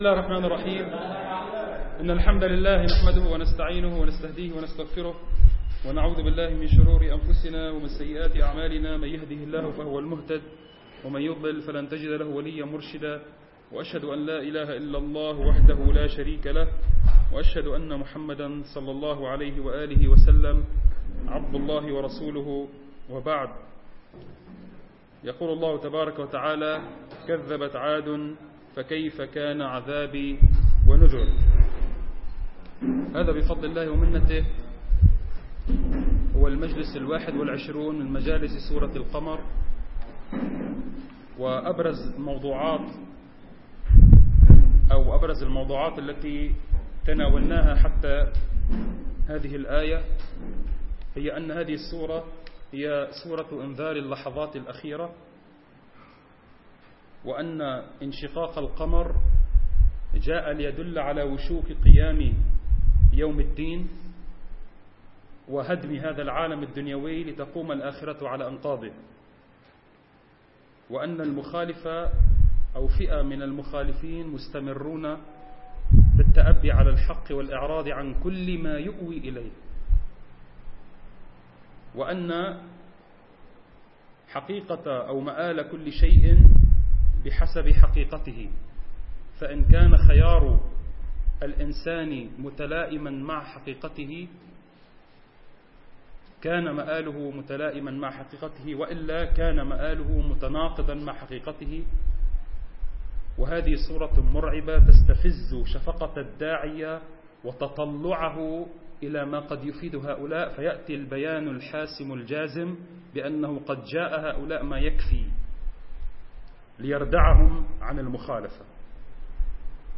المكان : مبنى جماعة عباد الرحمن